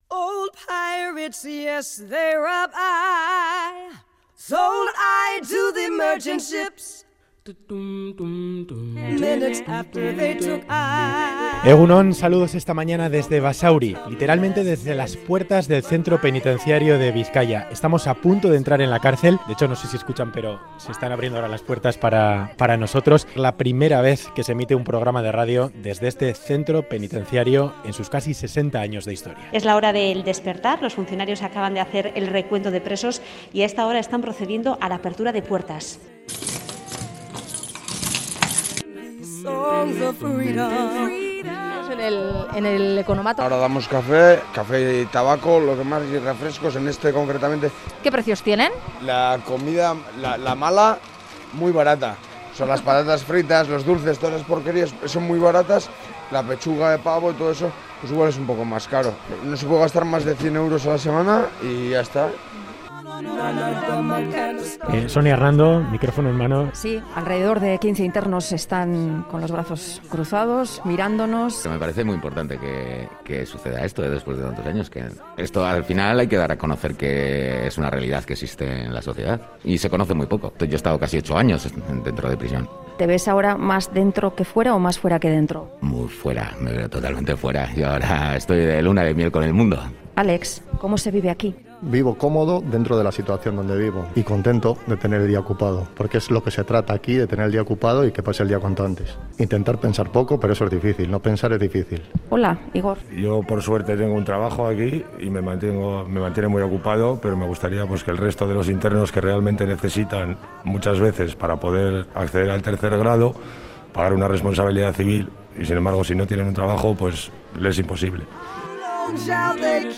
Programa especial desde el centro penitenciario Bizkaia. La primera vez que se emite un programa de radio desde Basauri en sus 60 años de historia.